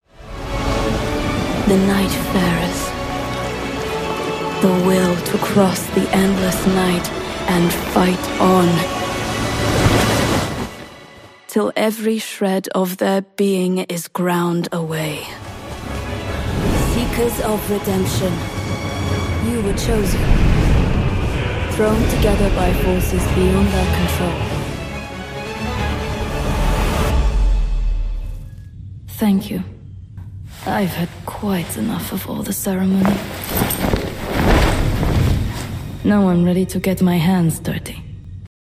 RP ('Received Pronunciation')
Fantasy, online, Gaming, acting, confident, powerful